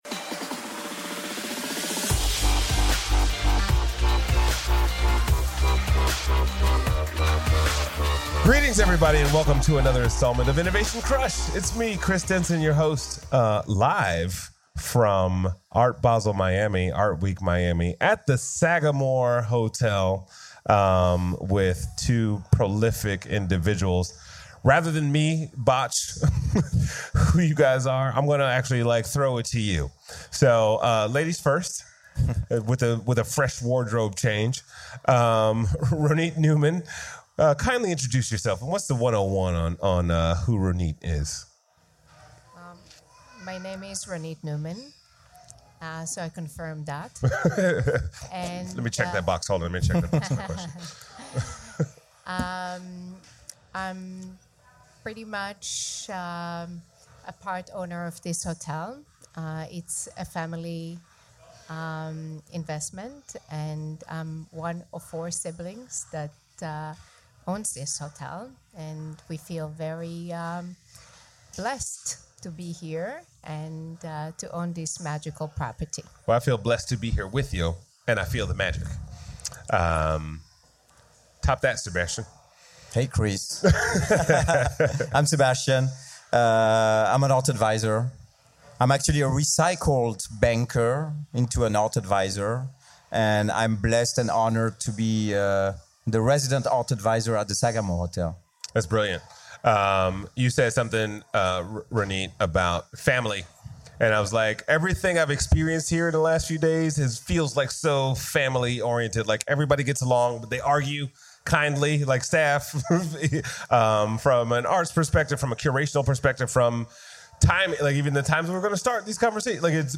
Showcasing the works of Banksy, Basquiat, Keith Haring, and more - including an artificially intelligent interactive street art experience - the Sagamore Hotel was the home of Innovation Crush during Art Week Miami 2017.